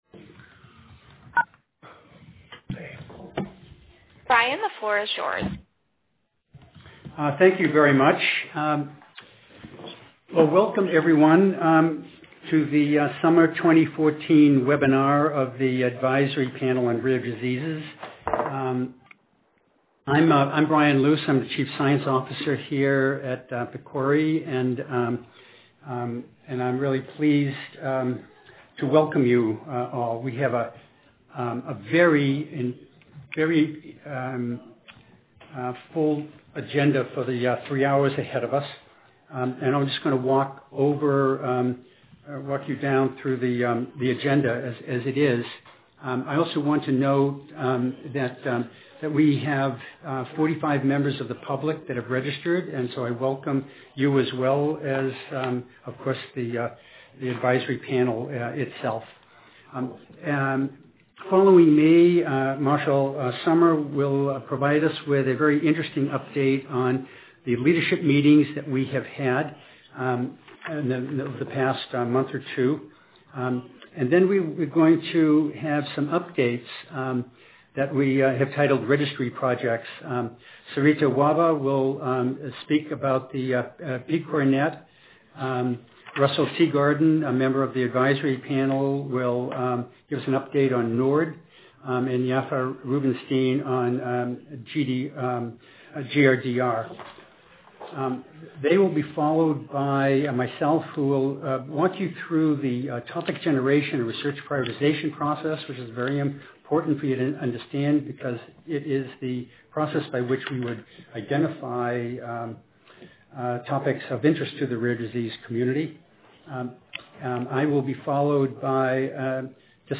Listen to the Teleconference Audio Recording